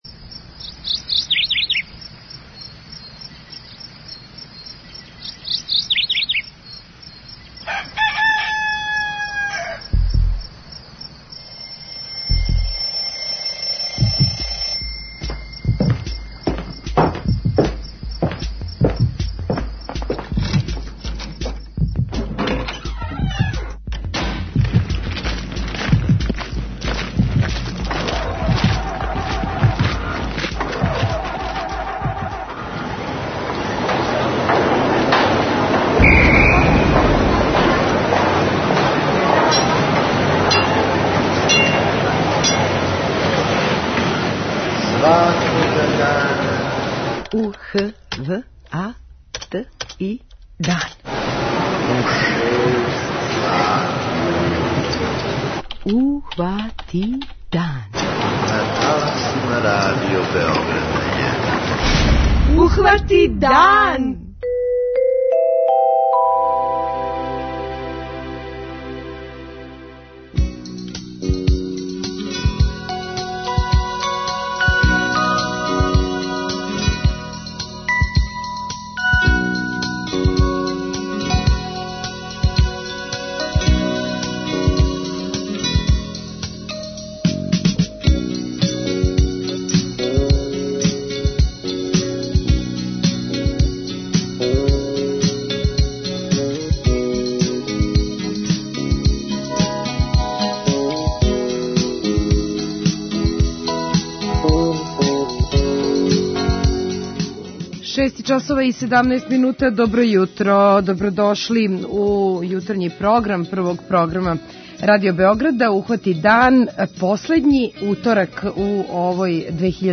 преузми : 29.54 MB Ухвати дан Autor: Група аутора Јутарњи програм Радио Београда 1!